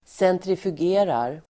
Ladda ner uttalet
centrifugera verb, centrifuge Grammatikkommentar: A & x Uttal: [sentrifug'e:rar] Böjningar: centrifugerade, centrifugerat, centrifugera, centrifugerar Definition: behandla i centrifug (to spin in a centrifuge)